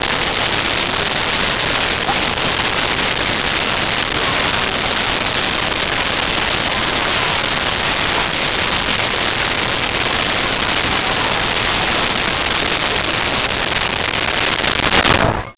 Crowd At Outdoor Mall
Crowd at Outdoor Mall.wav